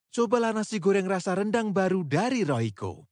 印尼语配音员（男3）